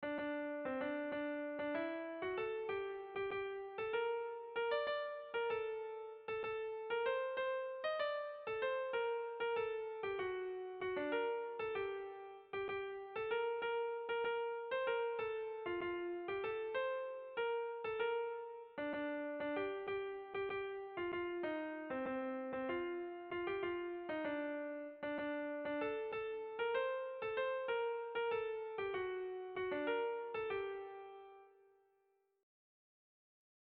Erlijiozkoa
Hamarreko handia (hg) / Bost puntuko handia (ip)
ABDEF